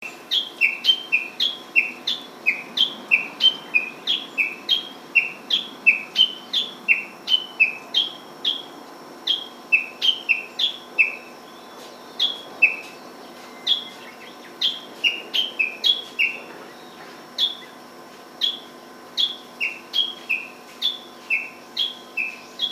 uguisu3.mp3